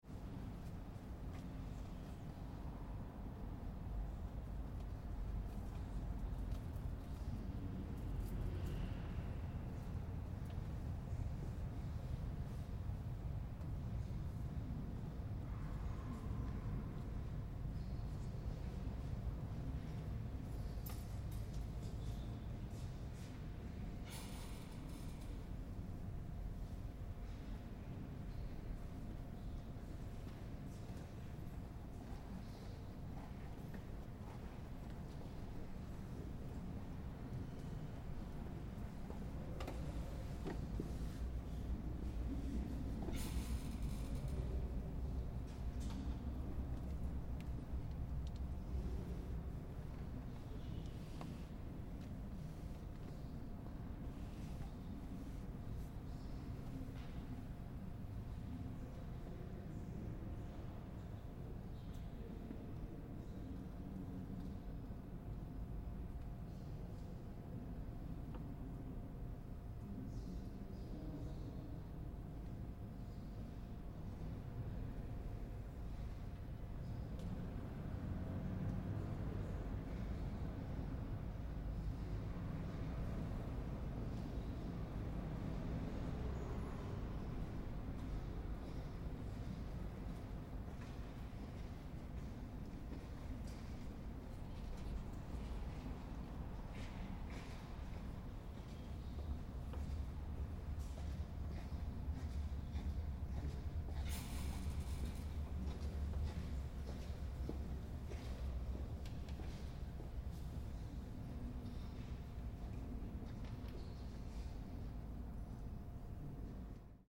Westminster Cathedral crowd presence
This soundscape explores the quiet stillness of Westminster Cathedral - the sound of slight whispers and occasional footsteps echo amongst the airy spaciousness of the church.